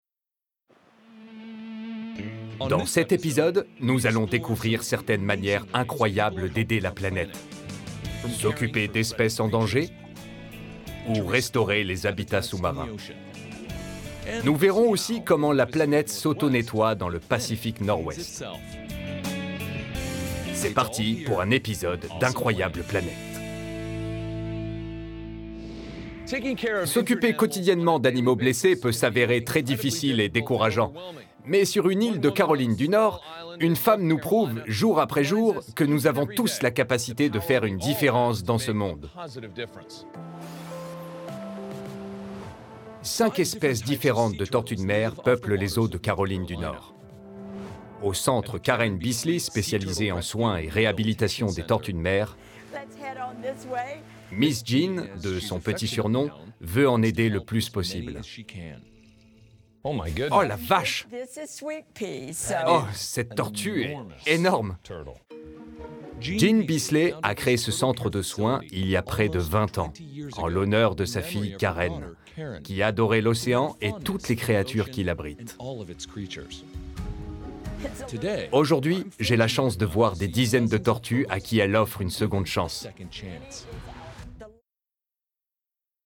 Démo Voice Over
20 - 55 ans - Baryton Ténor